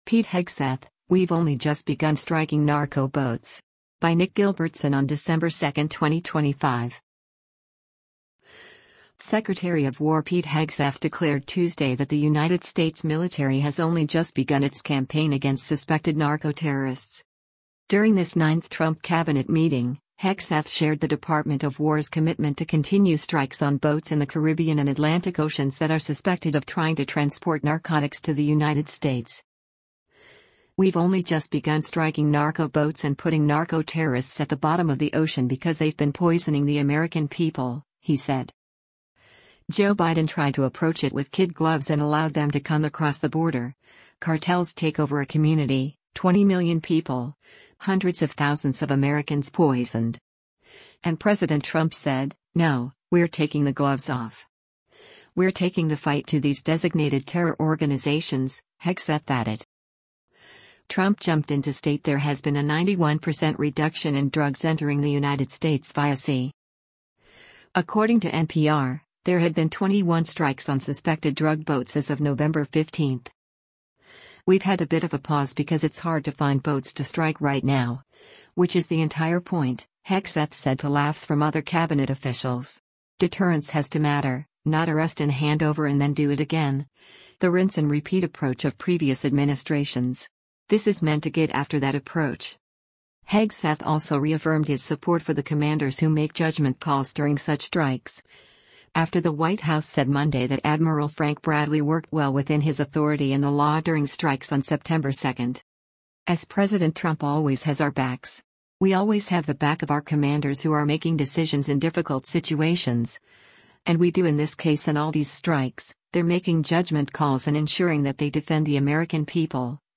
During this ninth Trump cabinet meeting, Hegseth shared the Department of War’s commitment to continue strikes on boats in the Caribbean and Atlantic Oceans that are suspected of trying to transport narcotics to the United States.
“We’ve had a bit of a pause because it’s hard to find boats to strike right now, which is the entire point,” Hegseth said to laughs from other cabinet officials.